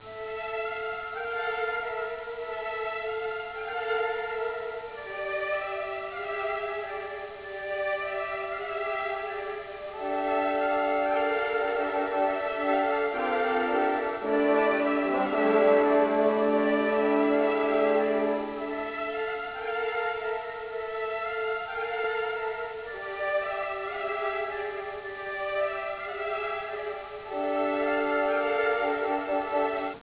Melodica, ma ricca di possenti marce romane
Original track music